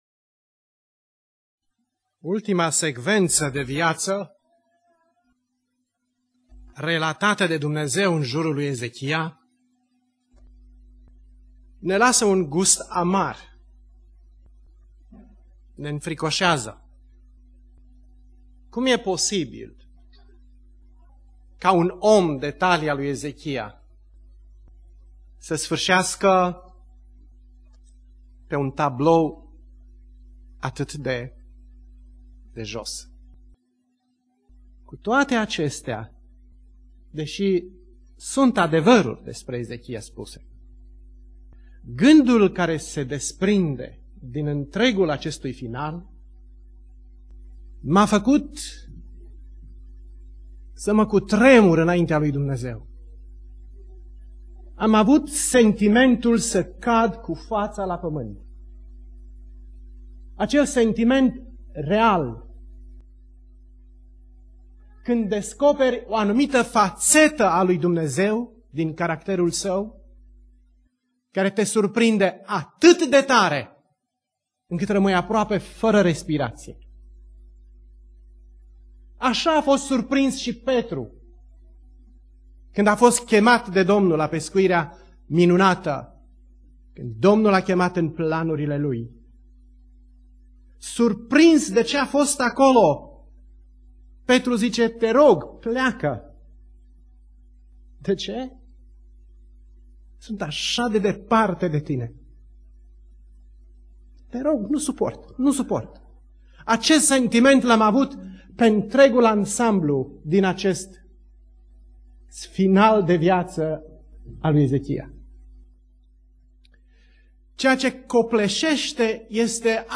Predica Aplicatie - Isaia 39